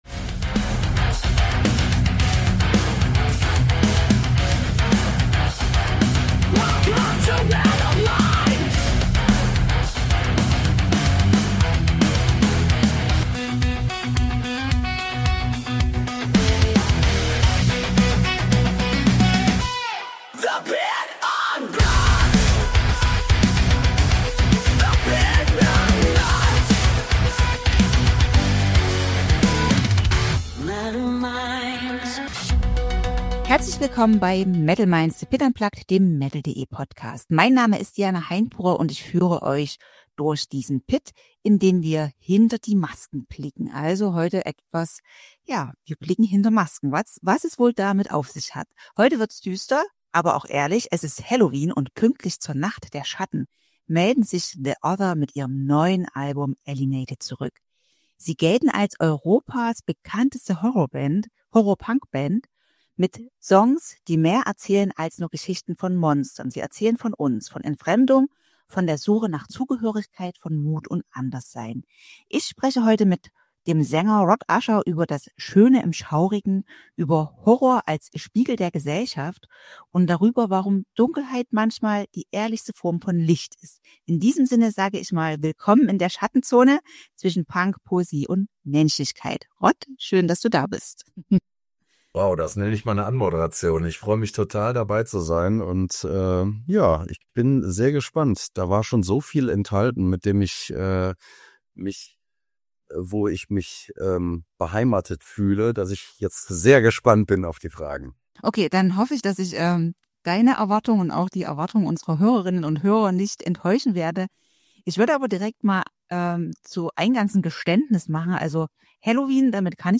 Im Gespräch geht es um die kreative Rückbesinnung auf die Goth-Punk-Wurzeln, um Songwriting-Nächte zwischen Schrammel-Akkorden und Ohrwurm-Refrains, um Subkultur, Verantwortung und den Wert kleiner Clubshows im Zeitalter der Eventisierung. Ein tiefgehendes, ehrliches Gespräch über Haltung, Zugehörigkeit – und darüber, warum Dunkelheit manchmal die klarste Form von Licht ist.